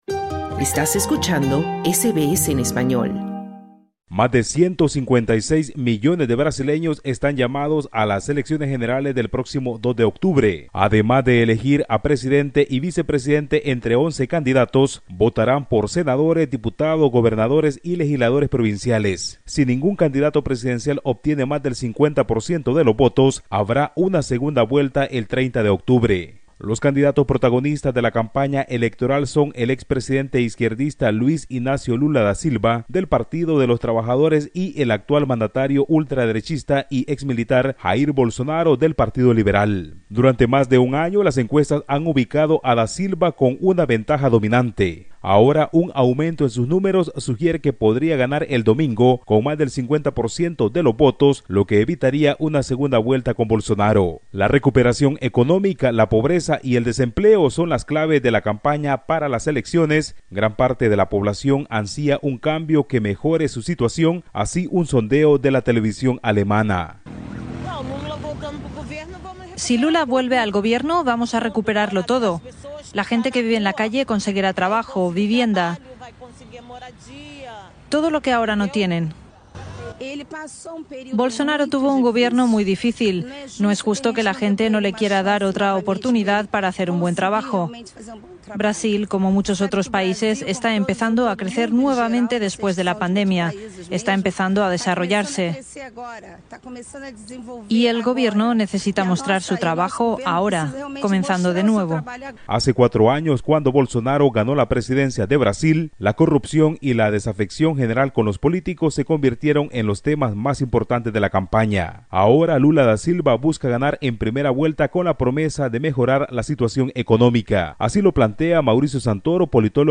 La campaña electoral de Brasil ha sido desde su comienzo una batalla cerrada entre el presidente brasileño de ultraderecha, Jair Bolsonaro, y el expresidente izquierdista Luis Inácio Lula da Silva. Pero según las encuestas existe una verdadera posiblidad de que gane la izquierda el domingo, cuando los brasileños acudan a las urnas para elegir el próximo gobierno. Escucha el informe del corresponsal de SBS Spanish en Latinoamérica